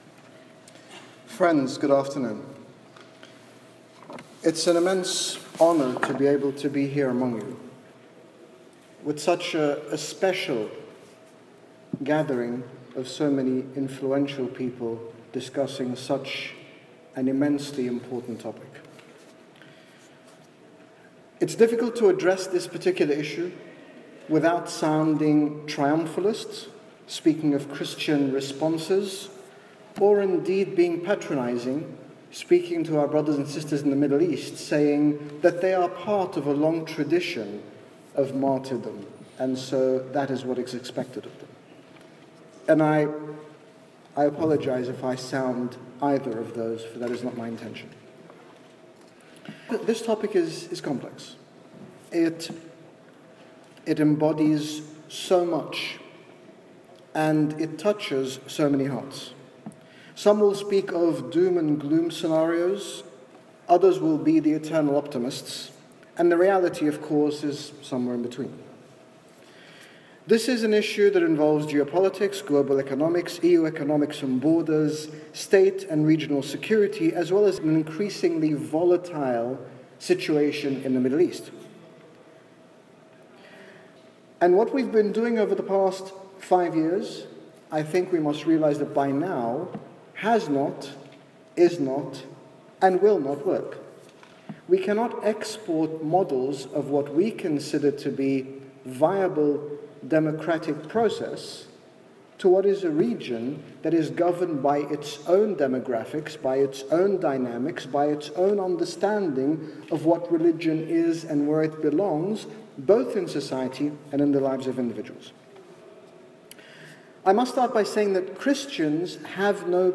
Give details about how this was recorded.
Conference